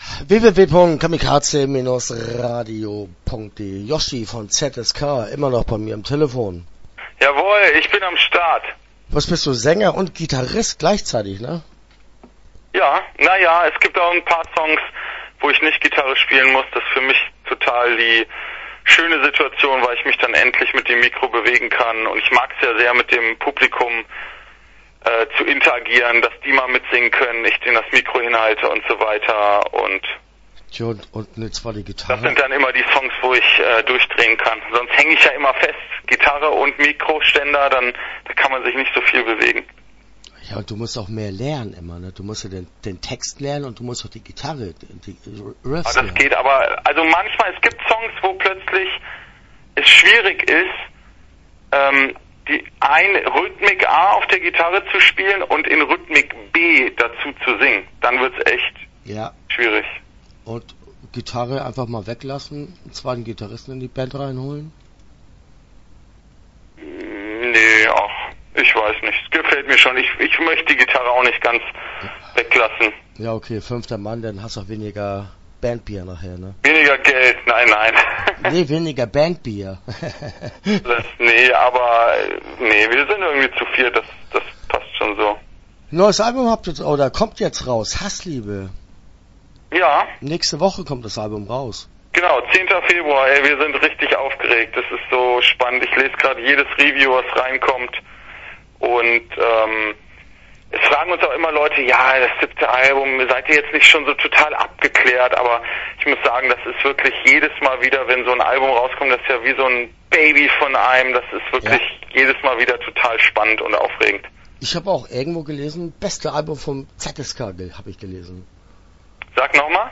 ZSK - Interview Teil 1 (12:03)